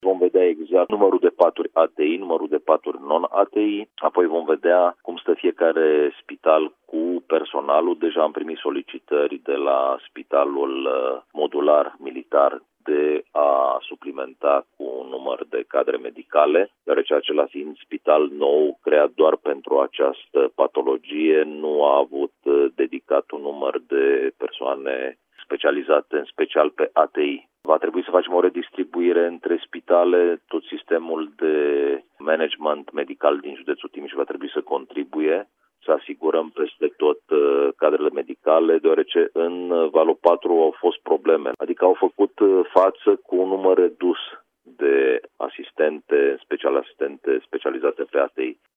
Subprefectul de Timiș va discuta mâine cu toți managerii de spitale despre reorganizarea unităților, astfel încât să poată fi gestionat numărul în creștere a bolnavilor de COVID. Este necesară și o redistribuirea a cadrelor medicale între spitale, a precizat la Radio Timișoara, subprefectul Ovidiu Drăgănescu.